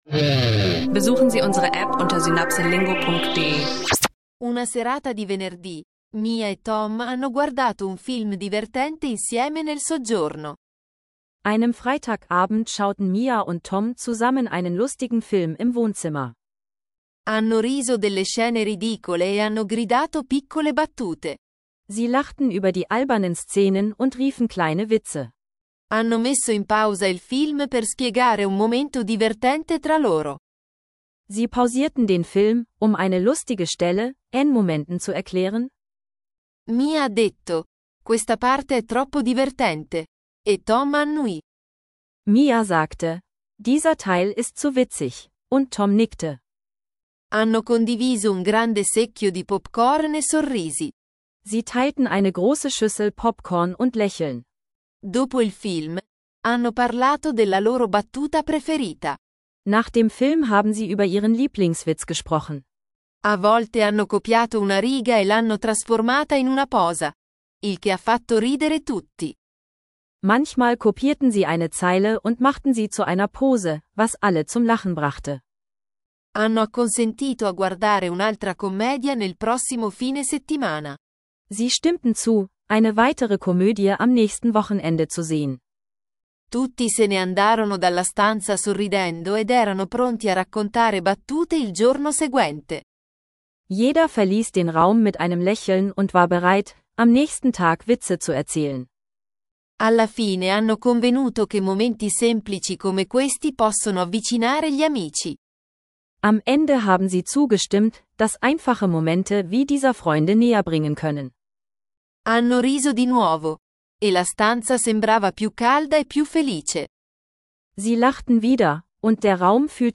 Lustige Filmmomente und Media Studies: Italienisch lernen mit Podcast, Alltagssprache und Dialogen über Filme und Medien